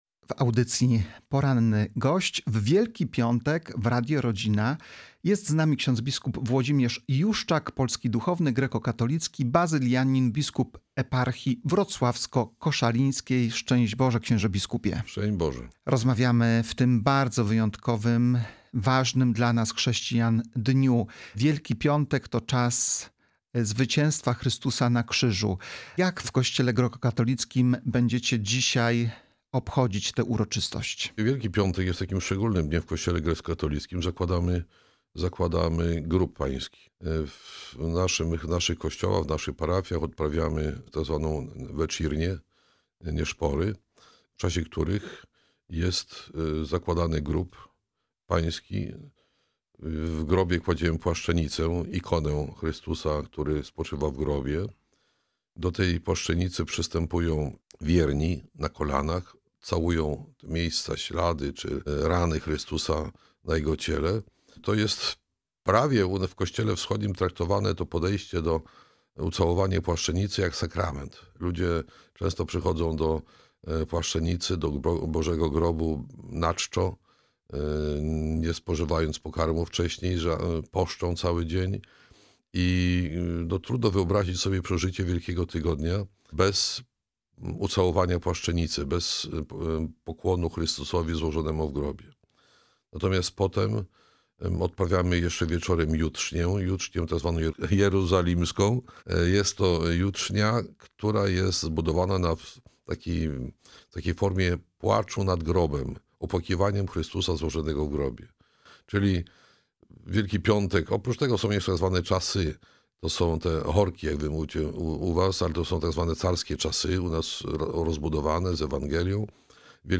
Gościem audycji „Poranny Gość” w Radio Rodzina był ks. bp Włodzimierz Juszczak – duchowny greckokatolicki, biskup eparchii wrocławsko-koszalińskiej. W rozmowie opowiedział o tym, jak Kościół greckokatolicki przeżywa Wielki Post i Święta Zmartwychwstania Pańskiego, podkreślając bogactwo liturgii oraz duchowy wymiar paschalnych nabożeństw.